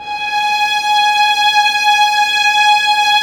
Index of /90_sSampleCDs/Roland LCDP13 String Sections/STR_Violins III/STR_Vls6 mf%f M